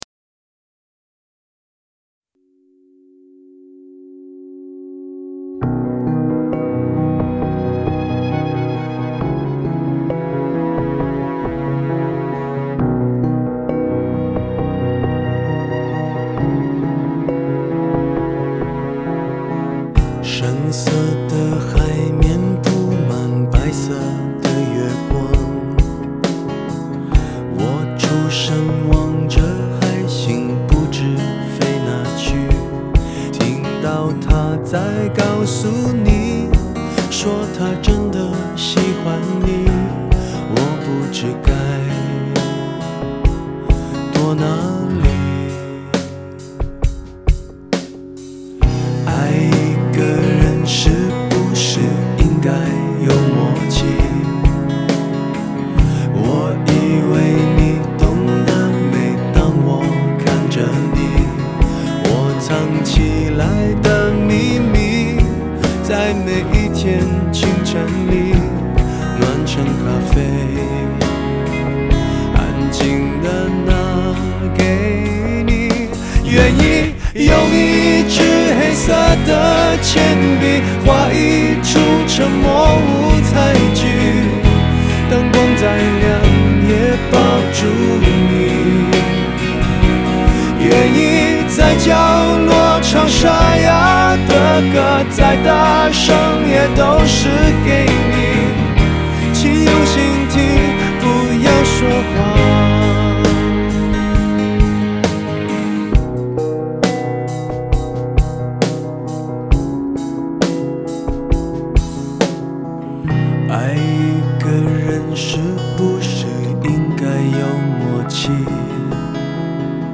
类别: 国风